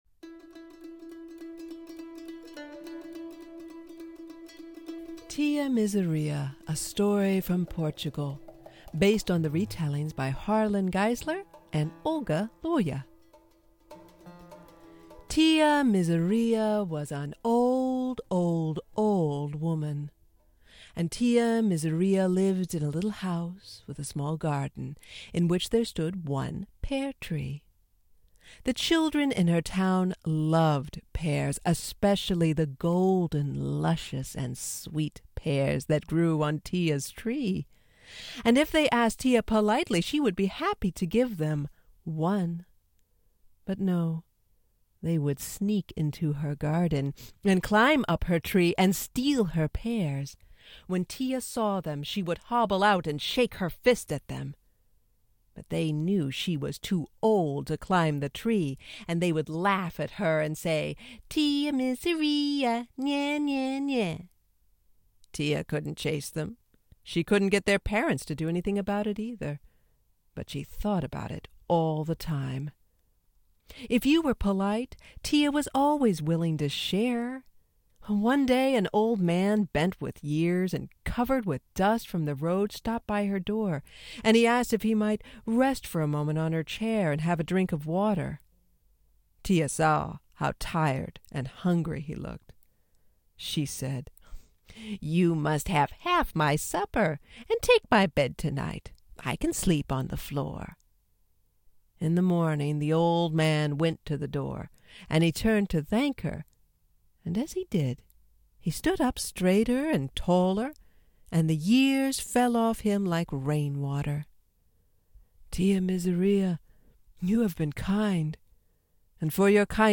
Here is one such story about a woman named Tía Miseria who thumbs her nose at death and, by doing so, brings something unexpected into the world. It is a story about a feisty, unconventional woman that I love to tell!